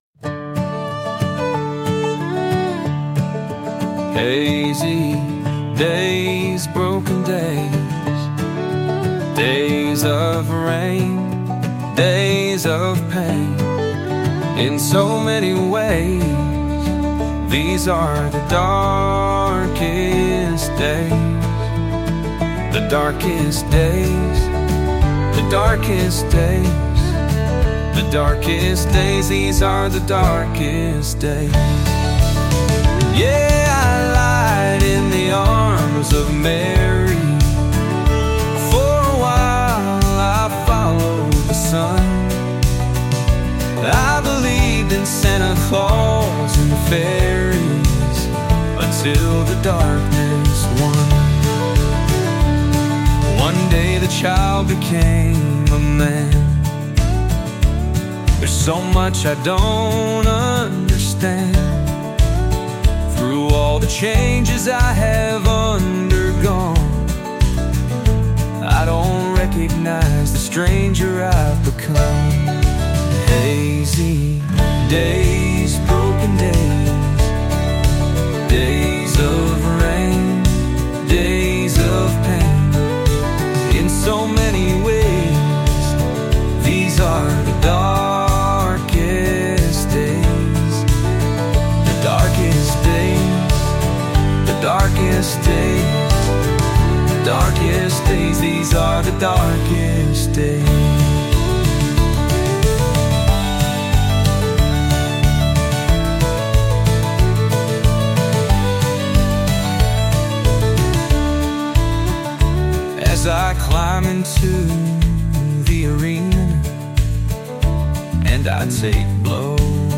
poignant and introspective country song